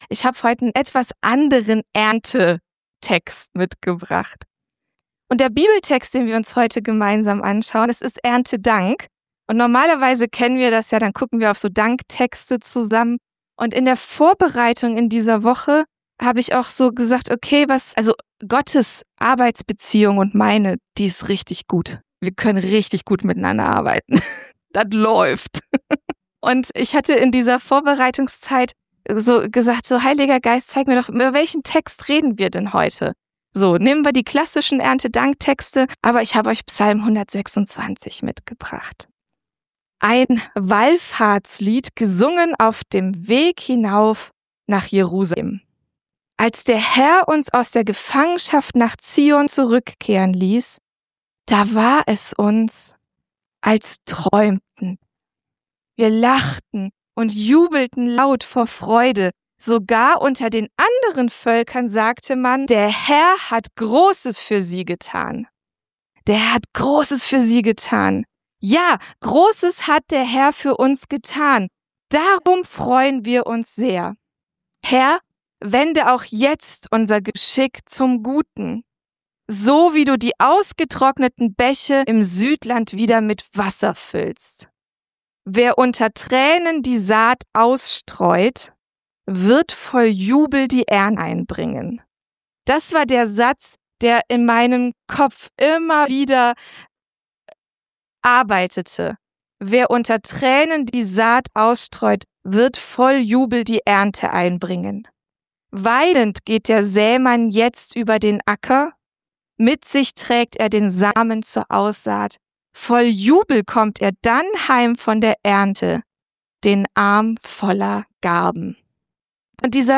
Leider wurde die Aufnahmedatei beim Bearbeiten beschädigt und musste aufwändig wiederhergestellt werden. Daher enthält diese Aufnahme ein paar Sprünge und kurze fehlende Wortteile. Die letzten Minuten fehlen leider auch.